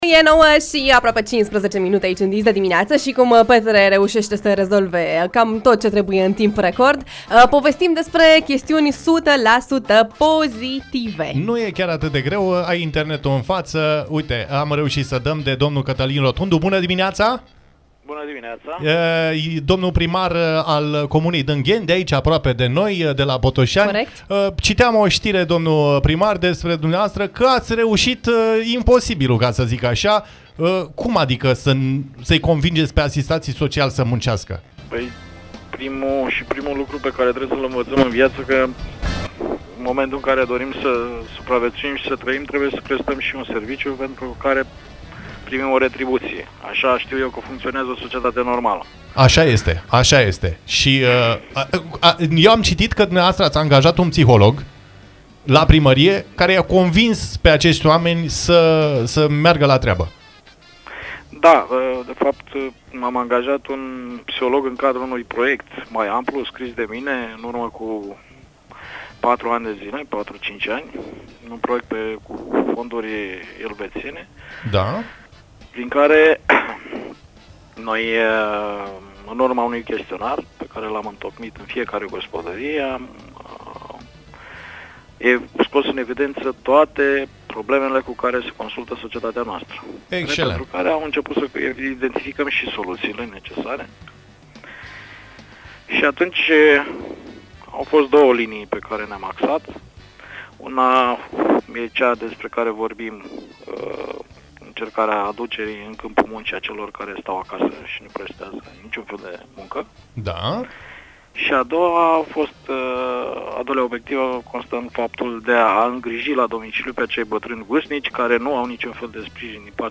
Cătălin Rotundu, primarul comunei Dângeni, în direct la „Dis de Dimineață”
Vă invităm să ascultați interviul integral, acordat de Cătălin Rotundu, primar al comunei Dângeni, mai jos: